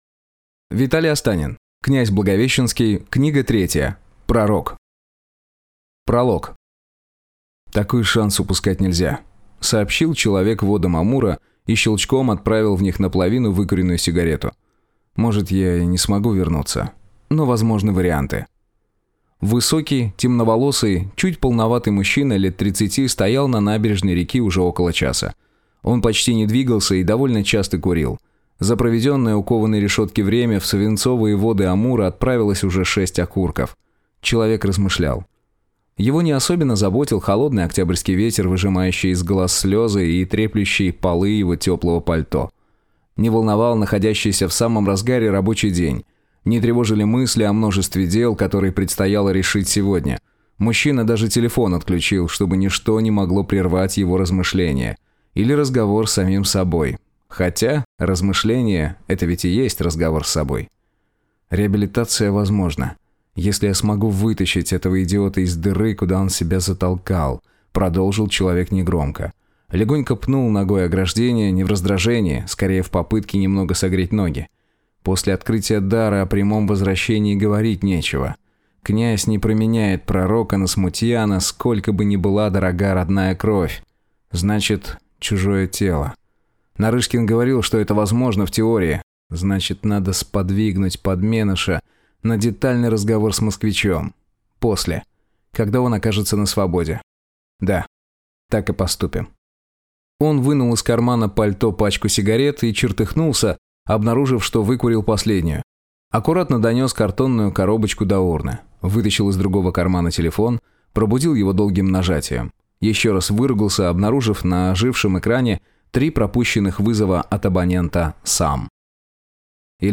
Аудиокнига Пророк | Библиотека аудиокниг
Прослушать и бесплатно скачать фрагмент аудиокниги